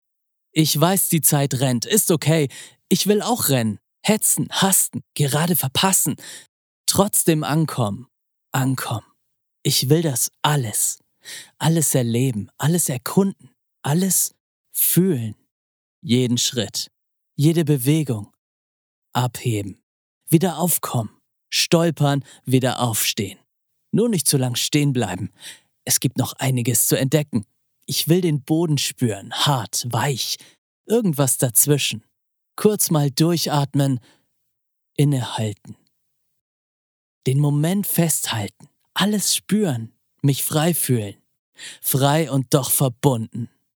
Reife Frauenstimme
Reife weibliche Sprecher Stimmen
Eine reife, warme Frauenstimme für weibliche Zielgruppen.